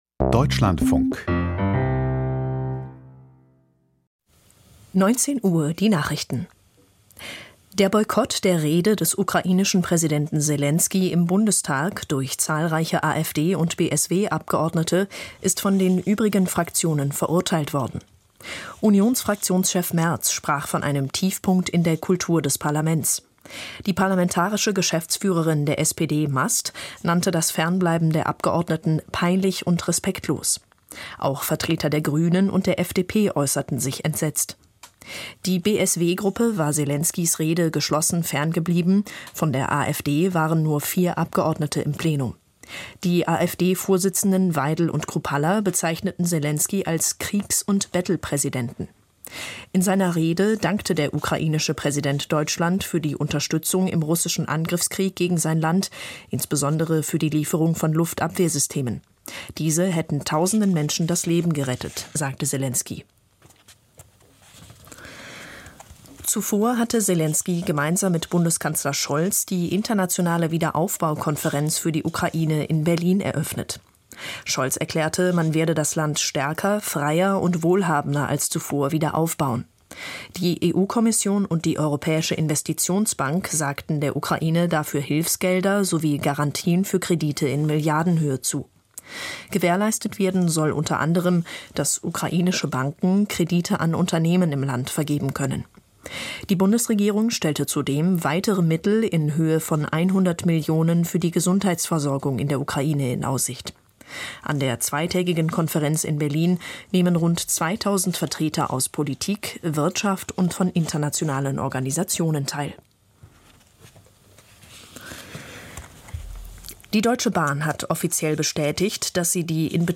Kommentar zu jungen Wählern und Europawahl-Ergebnis: Es braucht Politikangebote - 11.06.2024